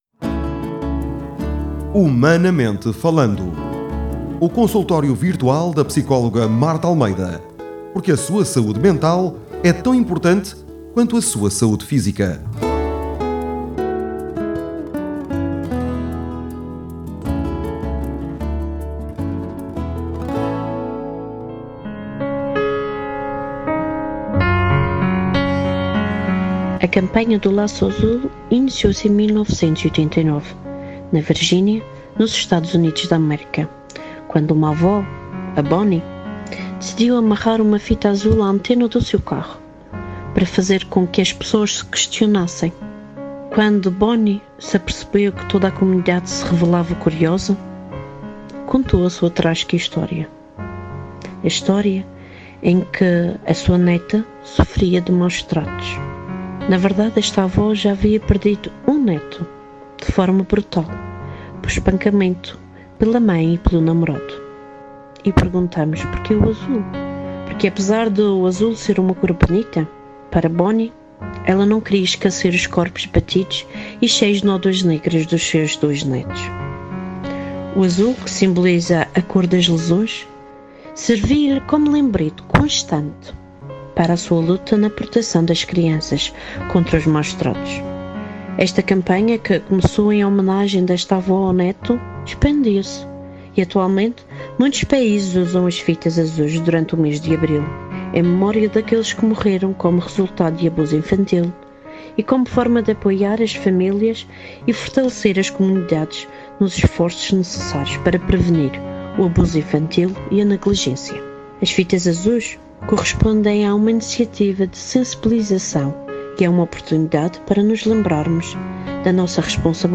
Dados preocupantes analisados numa conversa